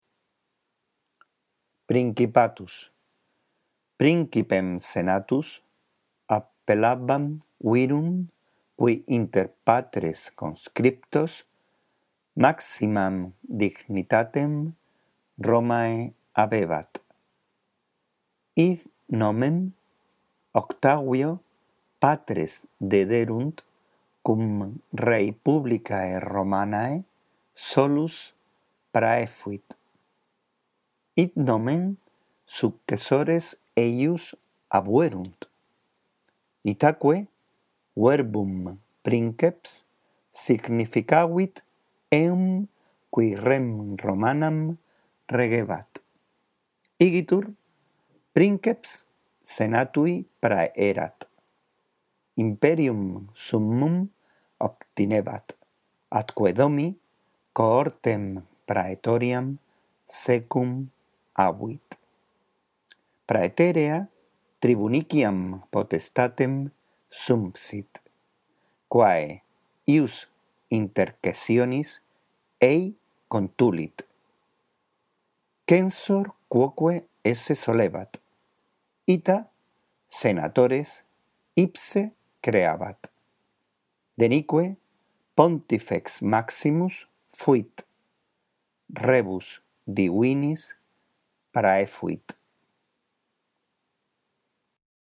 Tienes una lectura justo debajo del texto por si la necesitas.